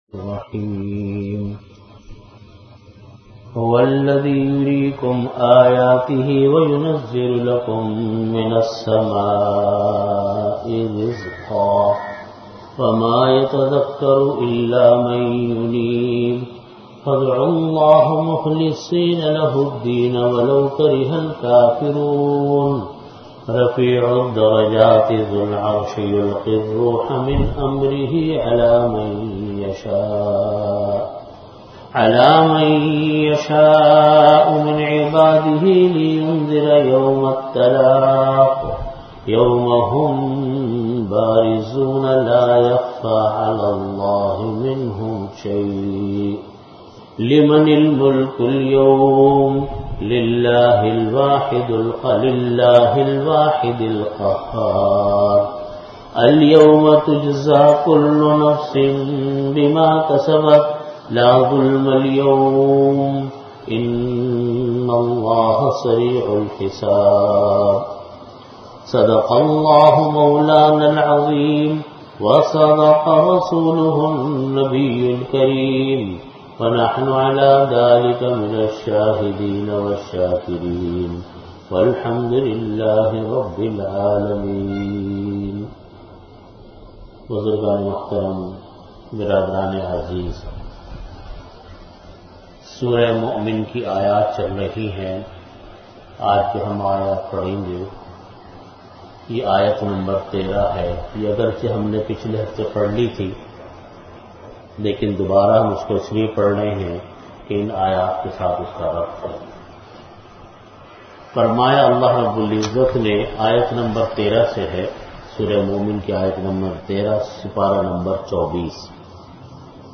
Delivered at Jamia Masjid Bait-ul-Mukkaram, Karachi.
Tafseer · Jamia Masjid Bait-ul-Mukkaram, Karachi